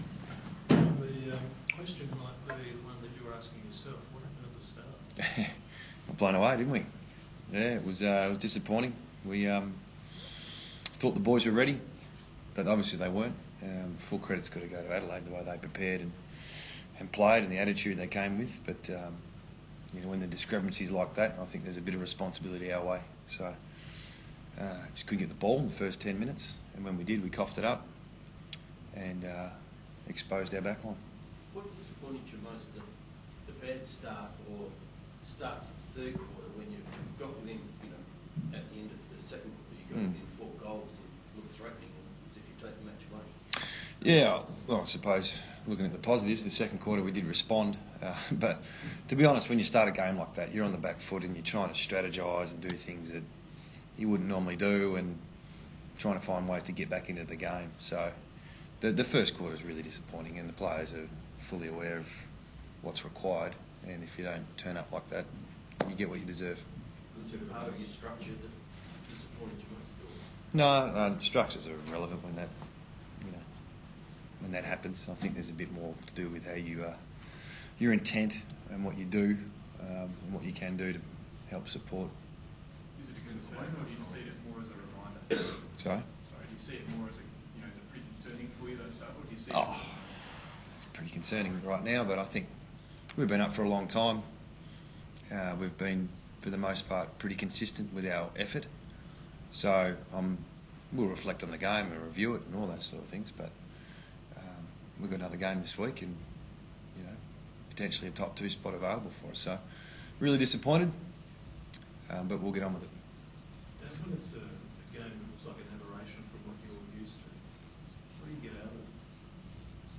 West Coast Eagles coach Adam Simpson after their big loss to the Adelaide Crows